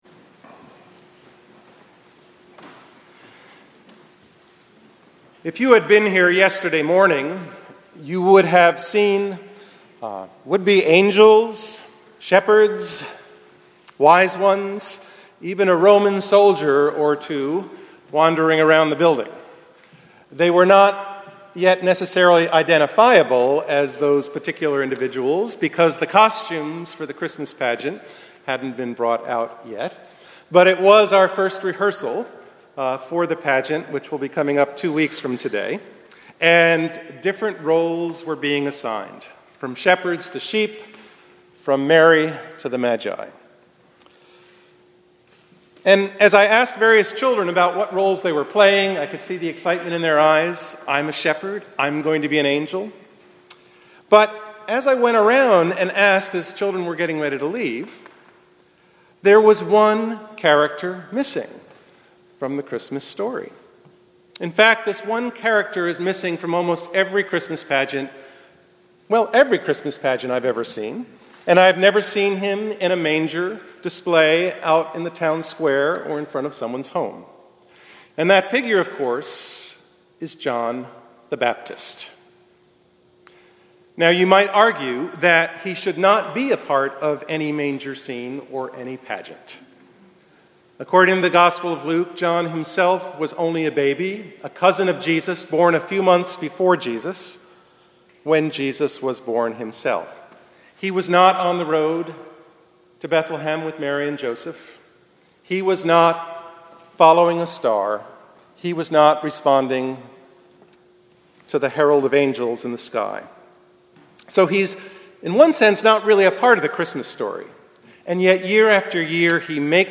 Sermon-12.8.13.mp3